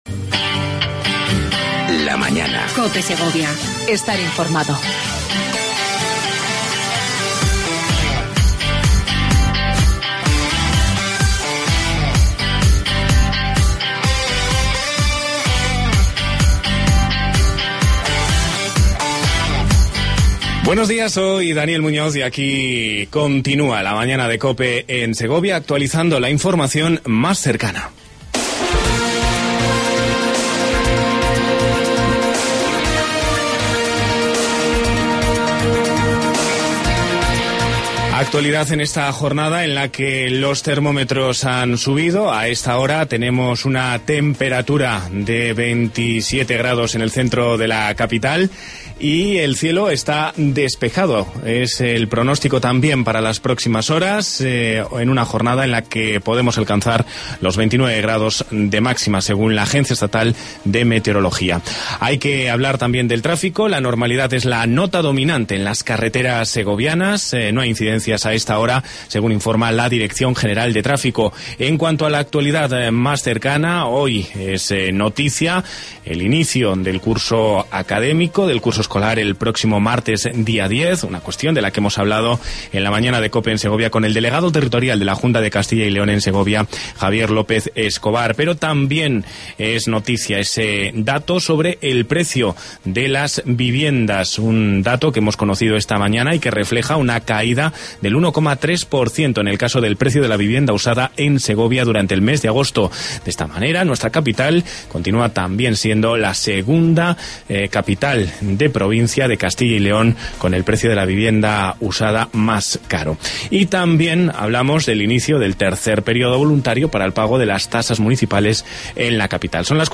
AUDIO: Entrevista con Francisco Vazquez, Presidente de la Diputación Provincial de Segovia.